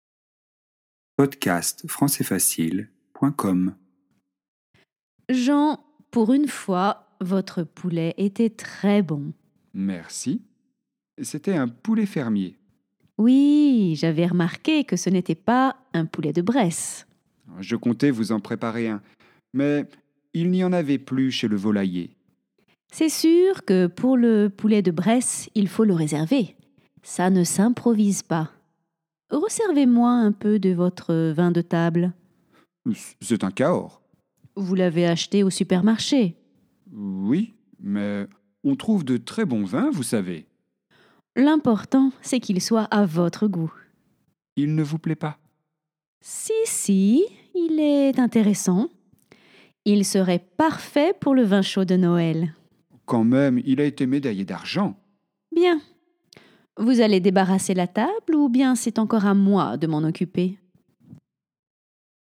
Dialogue niveau avancé